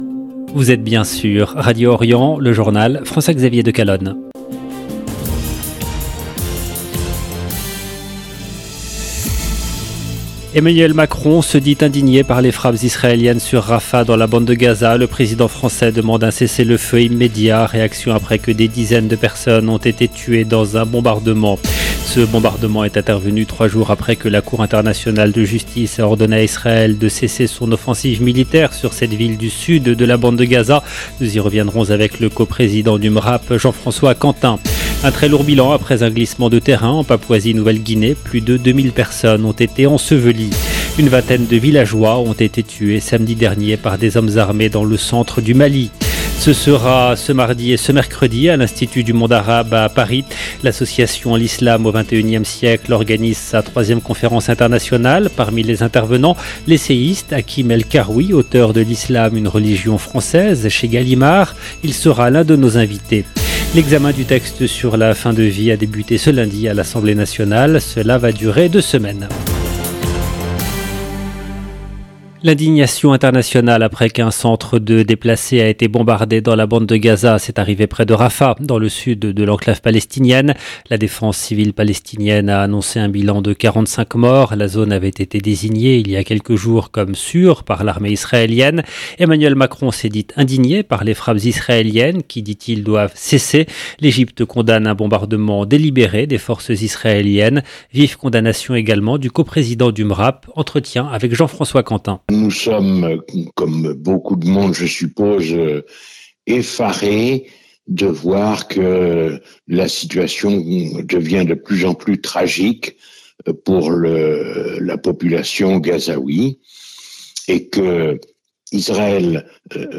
LE JOURNAL EN LANGUE FRANÇAISE DU SOIR DU 27/05/24